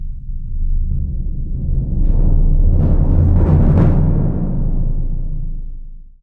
BIG BD 2B -R.wav